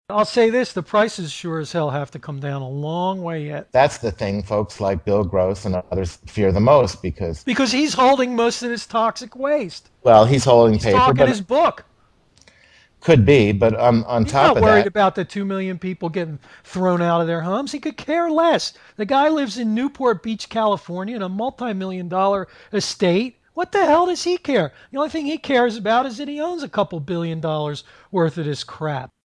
I was so mad, you can almost here me spitting into the mike.